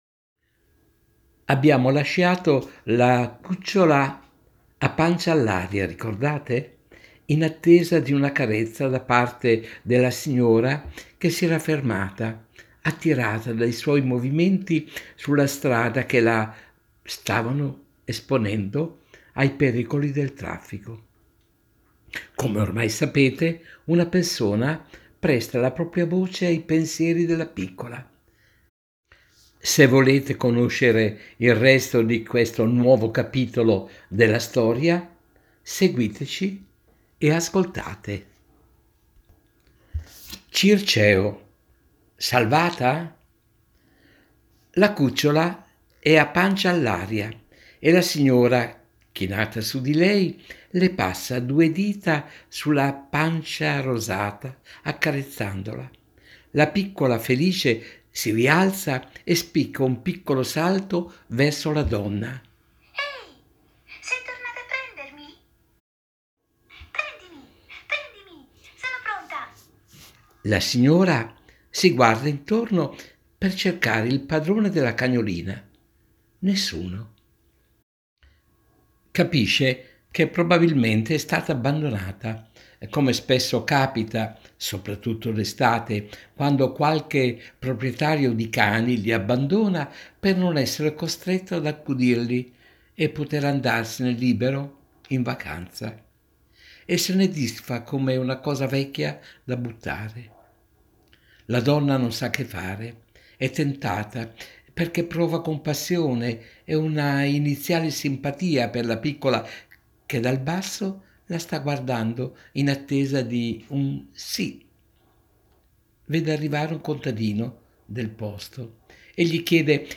Poiché la protagonista non può parlare come farebbe normalmente chiunque, le verrà data voce da una persona che ne riporterà fedelmente emozioni, gioie e paure.